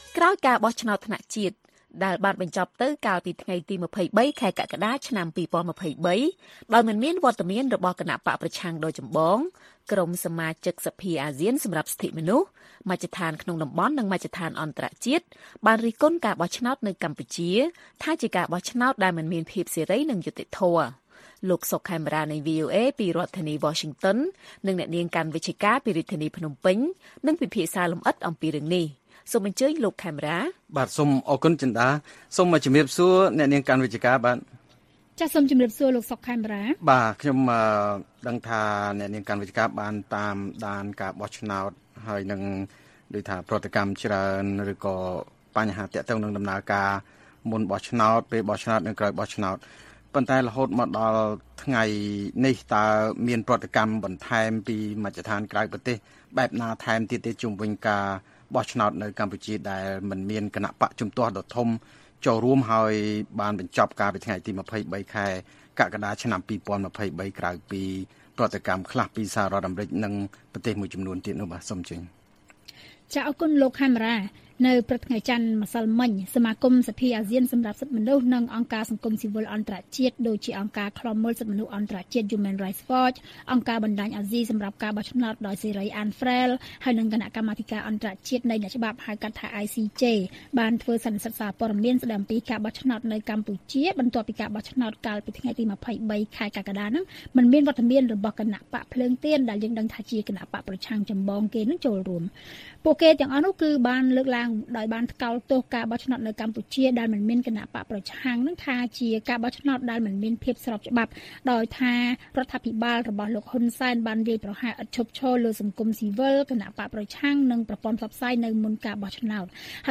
បទសន្ទនា VOA អំពីការបោះឆ្នោតនៅកម្ពុជា៖ មជ្ឈដ្ឋានអន្តរជាតិនិងតំបន់ រិះគន់ការបោះឆ្នោតនៅកម្ពុជាថាមិនសេរីនិងយុត្តិធម៌